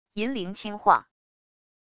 银铃轻晃wav音频